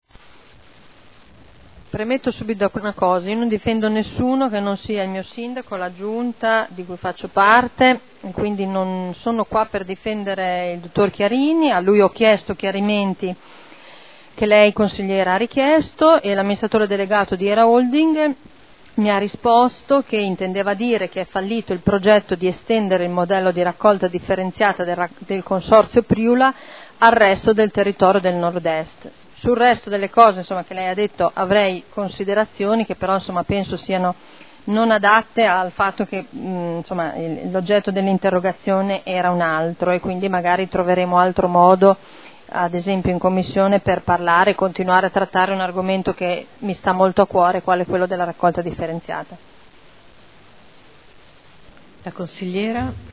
Seduta del 04/03/2013. Risponde.